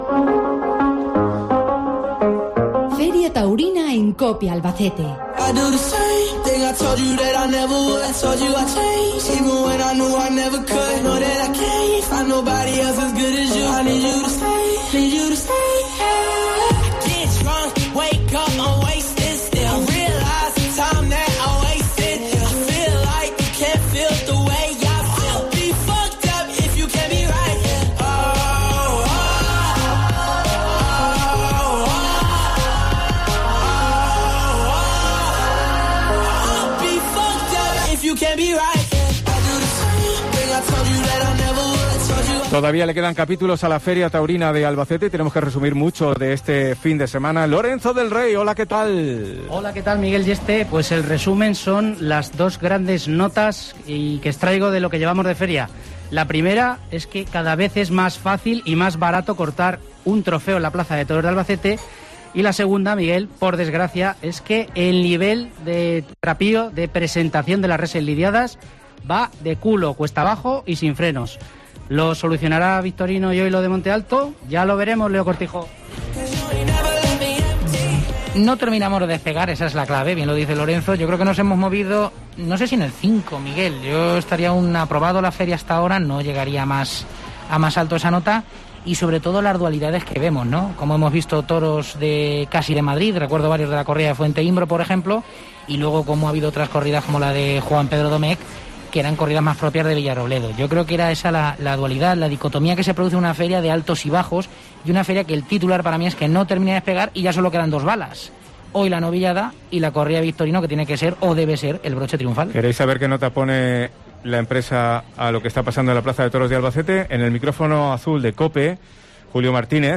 Tertulia El Albero: balance a mitad de Feria taurina